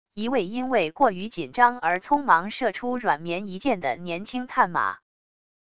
一位因为过于紧张而匆忙射出软绵一箭的年轻探马wav音频